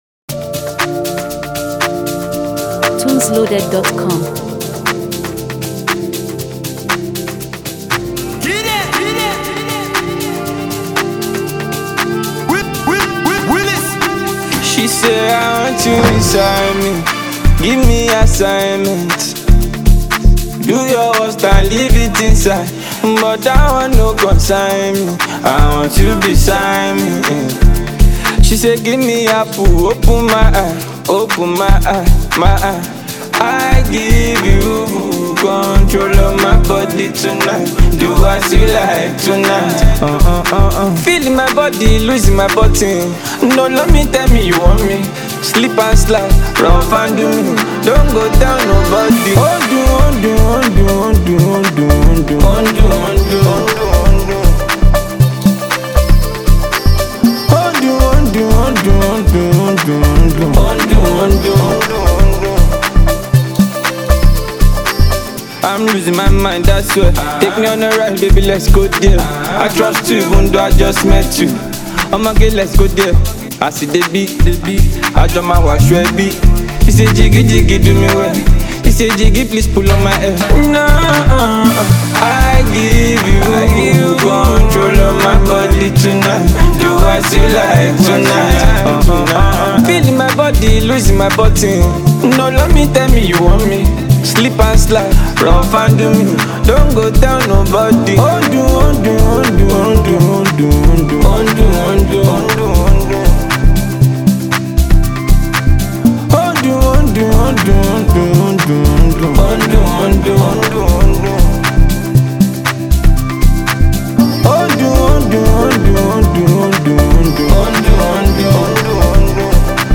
Blending Afrobeat influences with catchy rhythms